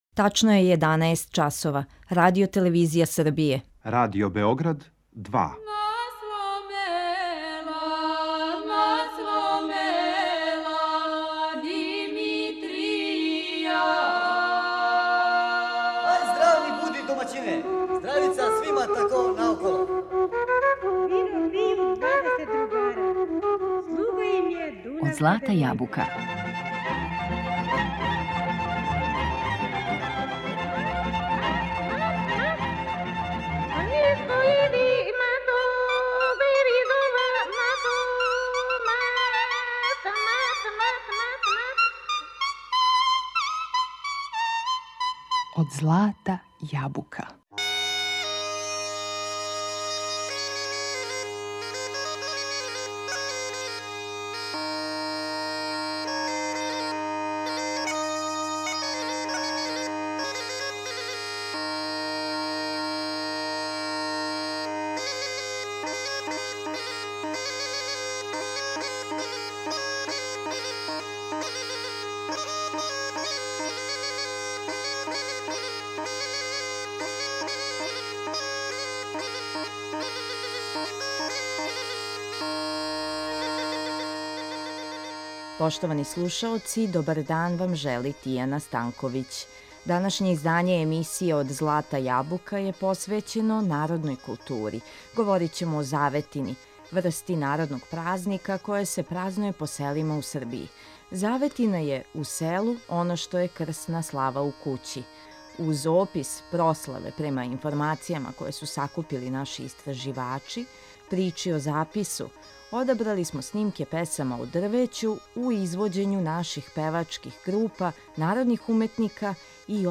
Говорићемо о заветини, врсти народног празника који се празнује по селима у Србији. Захваљујући нашим истраживачима, чућемо како изгледају те прославе, затим причу о запису ‒ светом дрвету, а одабрали смо пригодне снимке песама у извођењу певачких група и народних уметника песме и свирке.